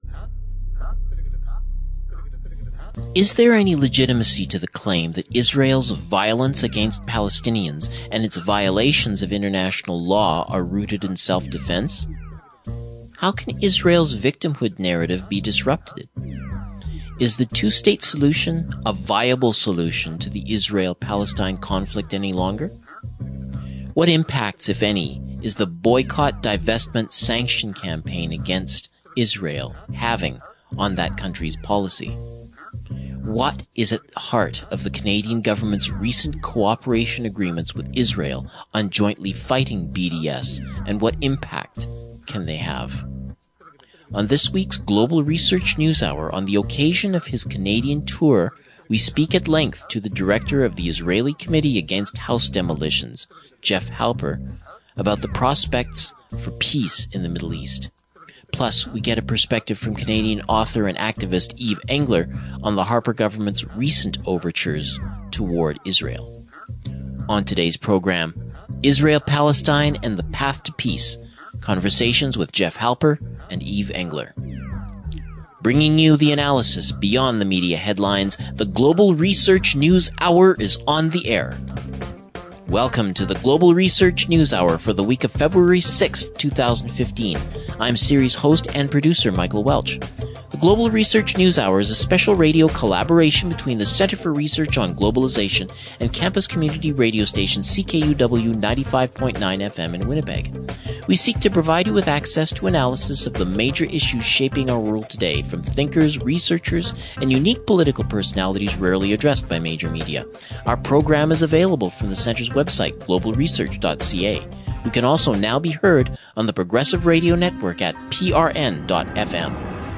Israel-Palestine and the Path to Peace: Conversations
File Information Listen (h:mm:ss) 0:59:19 GRNH_feb6,_2015_episode_92_session_mixdown.mp3 Download (7) GRNH_feb6,_2015_episode_92_session_mixdown.mp3 42,716k 0kbps Stereo Listen All